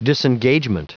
Prononciation du mot disengagement en anglais (fichier audio)
Prononciation du mot : disengagement